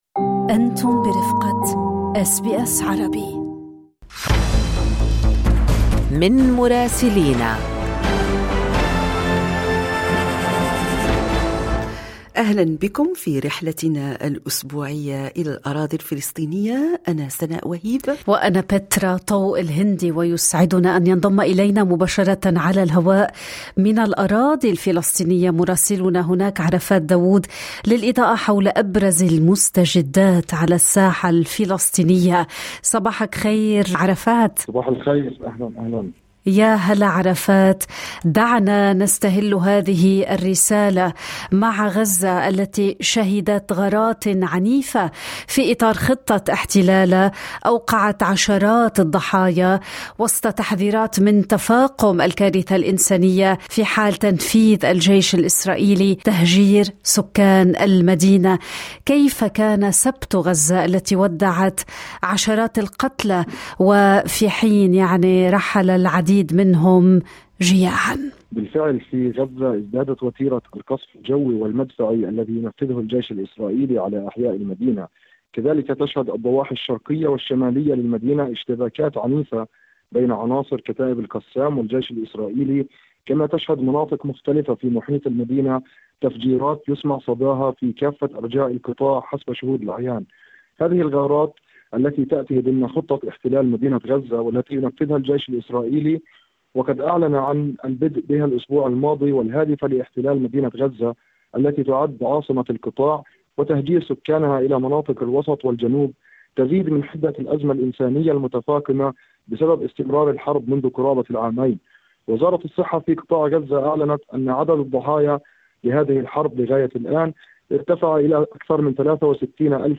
من مراسلينا: تصعيد عنيف في غزة واستعدادات لمواجهة أسطول الصمود…